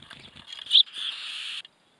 【威 嚇】[/AZ/]
シジュウカラが抱卵中の巣箱をスズメがのぞいた時の鳴き声
特定の周波数は無く、白色雑音に近い音を出す。
ネコのシャーに類似する。